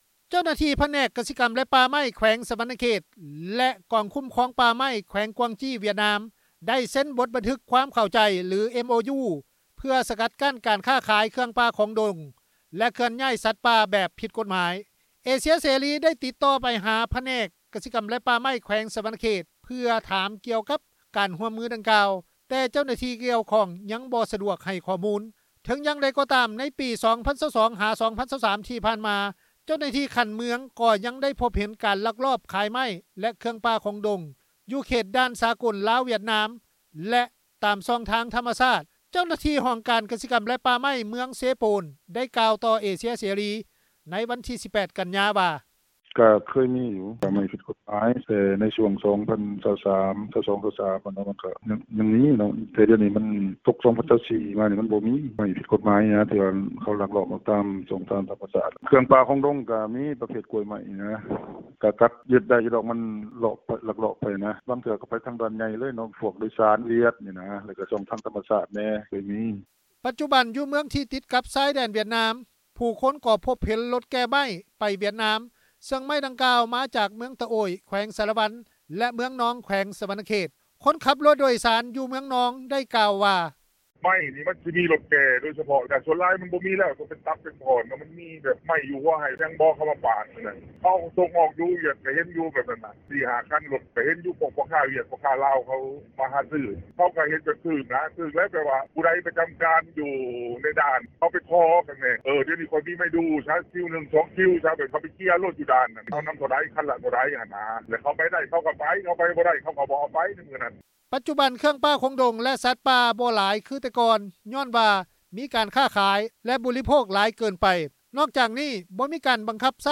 ຄົນຂັບລົດໂດຍສານ ຢູ່ເມືອງນອງ ໄດ້ກ່າວວ່າ:
ຊາວເມືອງທ່າປາງທອງ ແຂວງ ສະຫວັນນະເຂດ ໄດ້ກ່າວວ່າ: